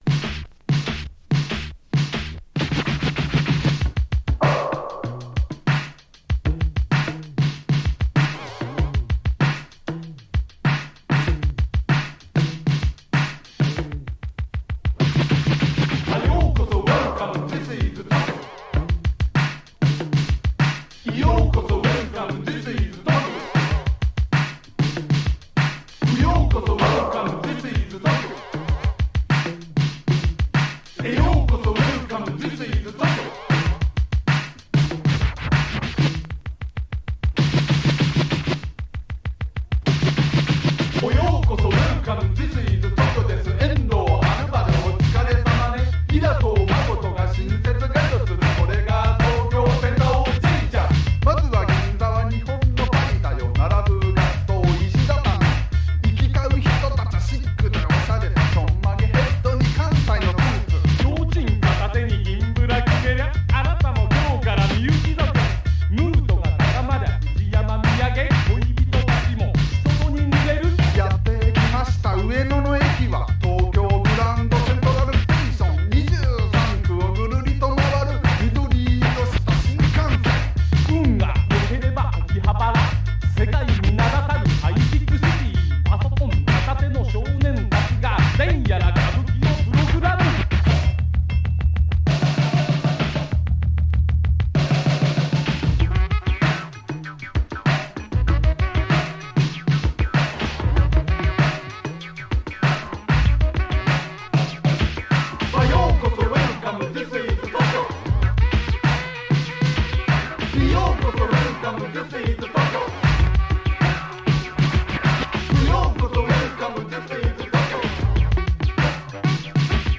(vocal)
Basic track recorded at Evergreen Studio, New York City
Overdubbed at Smoky Studio, Tokyo
Mixed at CBS Sony Roppongi Studio, Tokyo
rap
talking drum